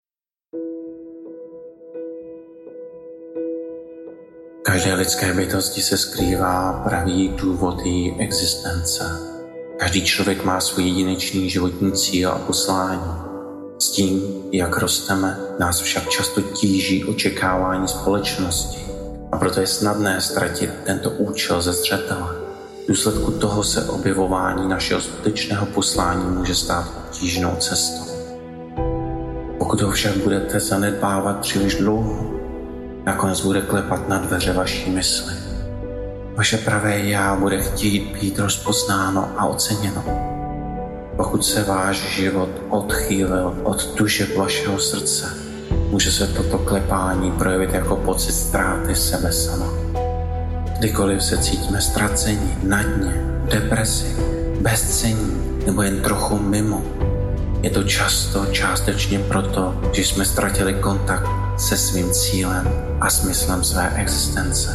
AudioKniha ke stažení, 1 x mp3, délka 11 min., velikost 9,8 MB, česky